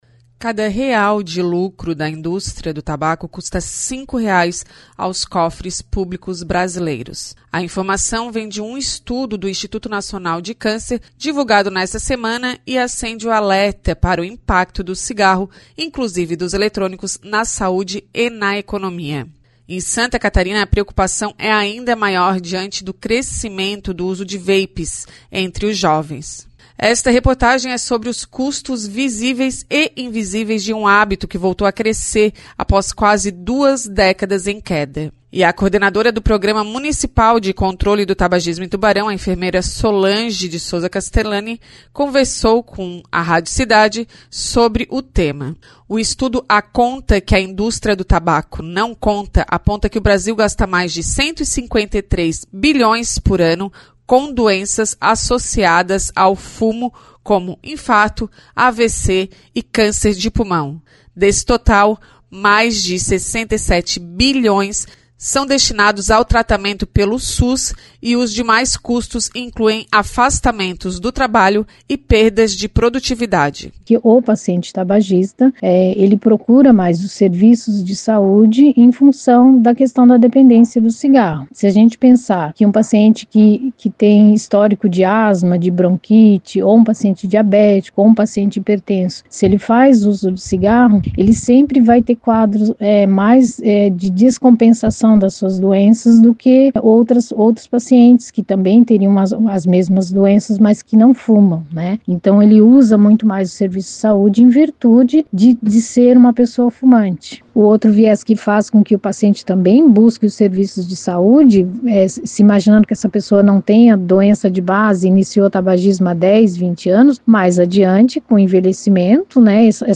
Escute abaixo a reportagem especial, da Rádio Cidade Tubarão, sobre o estudo do INCA e os custos reais do cigarro para o Brasil. A análise mostra o desequilíbrio econômico e social gerado pelo tabagismo, inclusive em Santa Catarina.